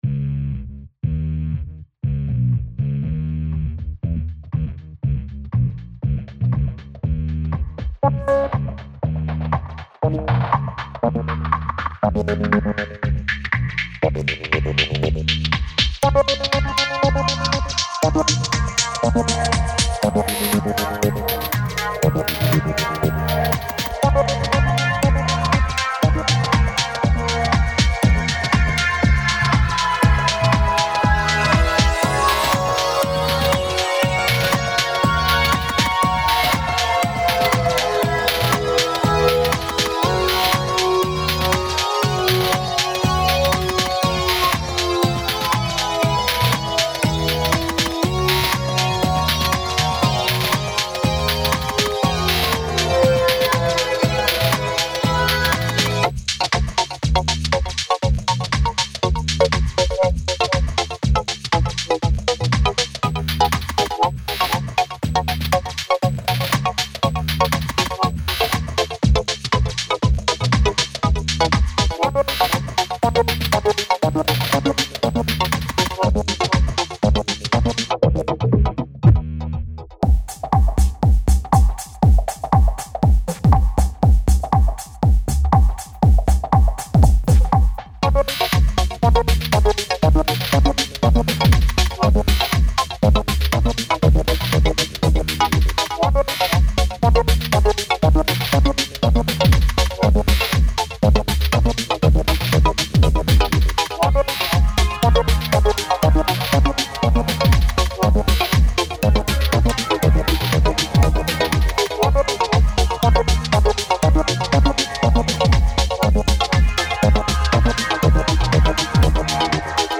Trying out the Synths in my upgrade of Reason …